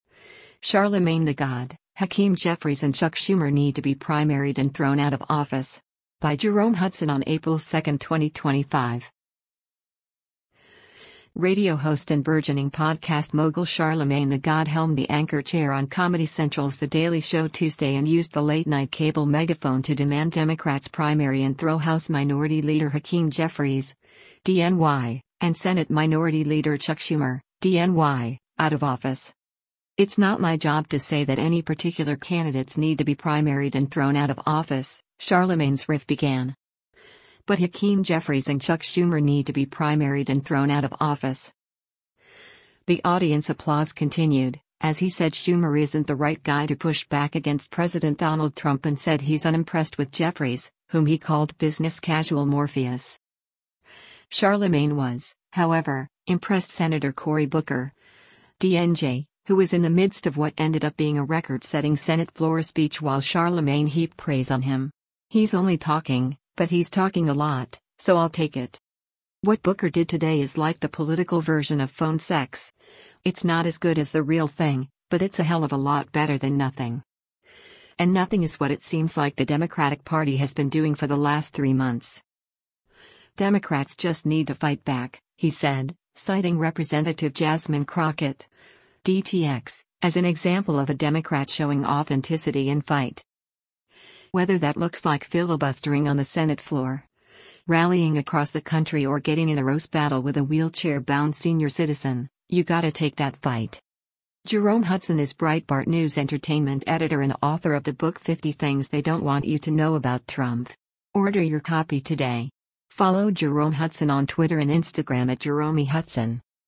Radio host and burgeoning podcast mogul Charlamagne tha God helmed the anchor chair on Comedy Central’s The Daily Show Tuesday and used the late-night cable megaphone to demand Democrats primary and throw House Minority Leader Hakeem Jeffries (D-NY) and Senate Minority Leader Chuck Schumer (D-NY) out of office.
The audience applause continued, as he said Schumer isn’t the right guy to push back against President Donald Trump and said he’s unimpressed with Jeffries, whom he called  “business casual Morpheus.”